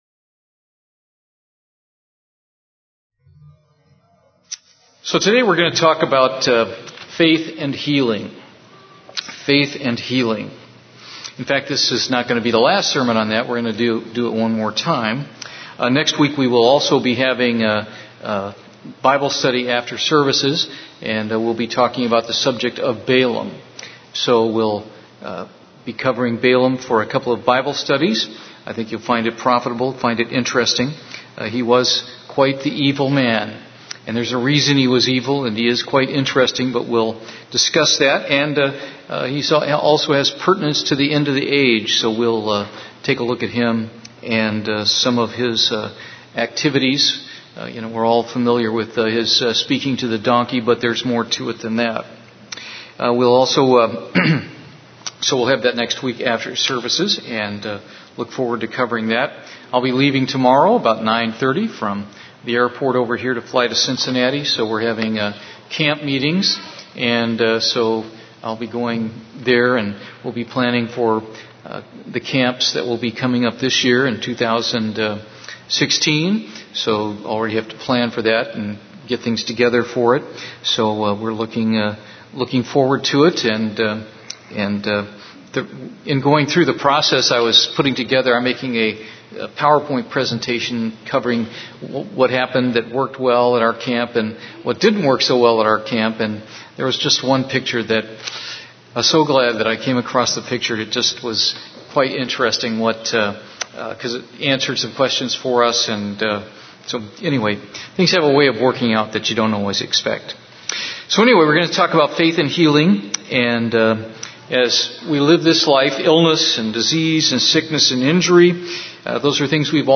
This sermon is the first of a two part sermon on the subject of faith and healing.